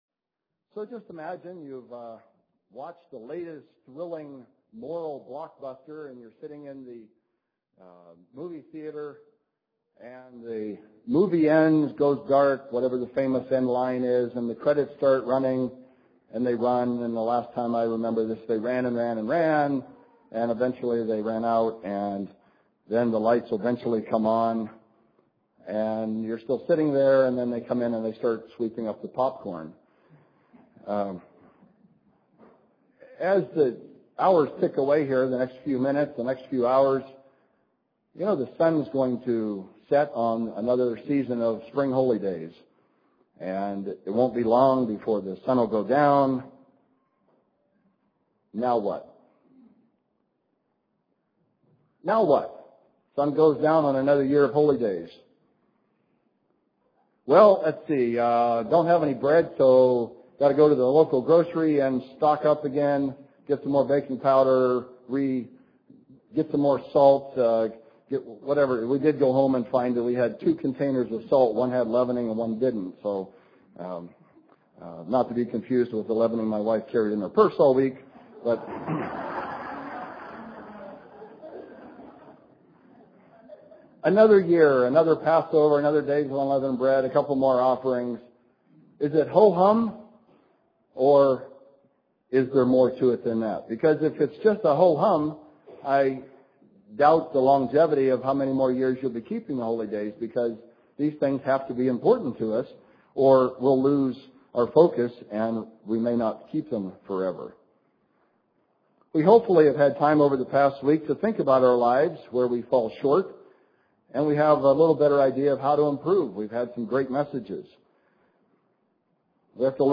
This message was given on the Last Day of Unleavened Bread.
Given in Spokane, WA
UCG Sermon Studying the bible?